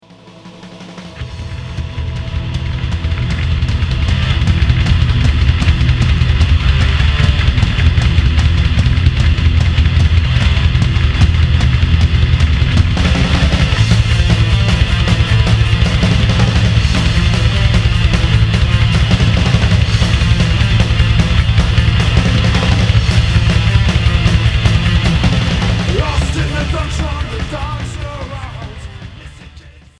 Heavy métal